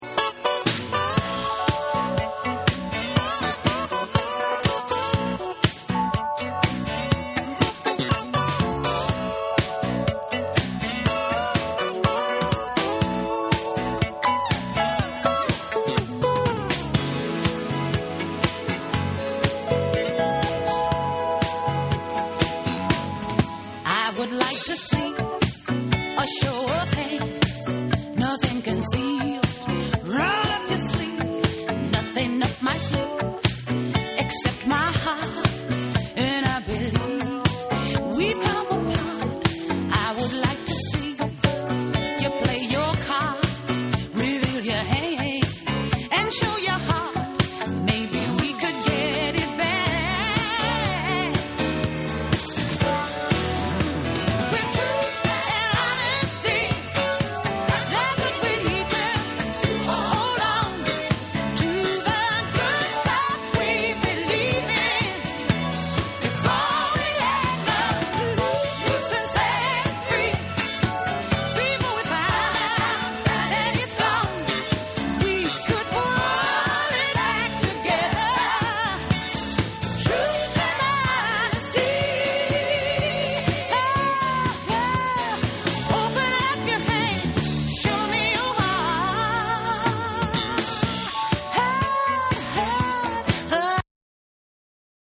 ブリージンなギターの旋律が心地よく、サビのこみ上げるメロディーが素晴らしい6も収録したオススメ盤！
SOUL & FUNK & JAZZ & etc